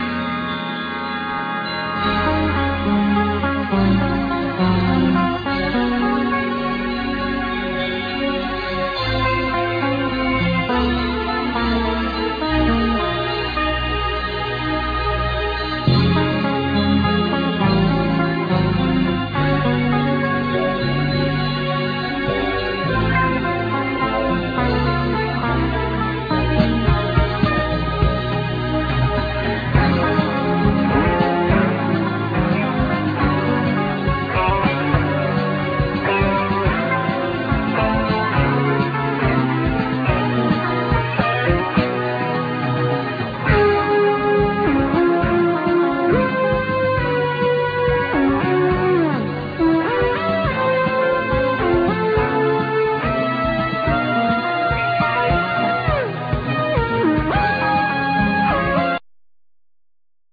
Alto+Soprano sax,Synthesizer
Keyboards
Piano
Tenor+Soprano sax.Violin
Guitar
Bass,Byan
Drums
Percussions